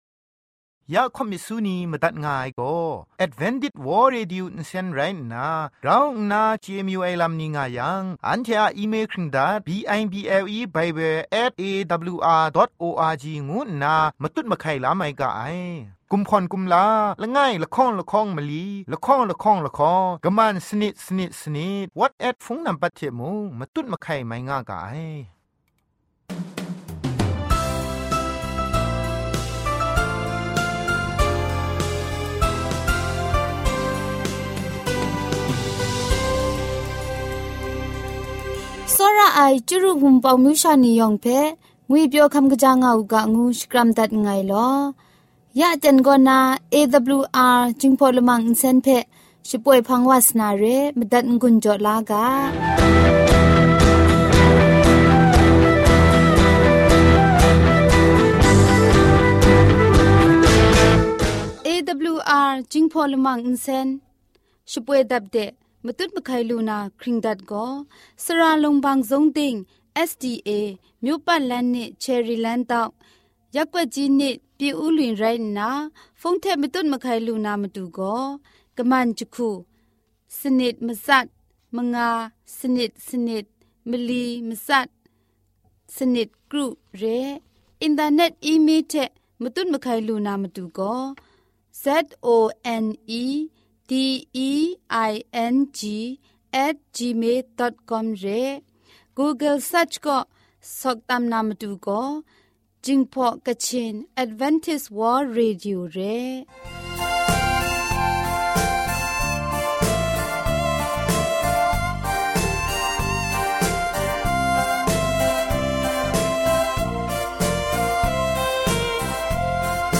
Gospel song,health talk,sermon.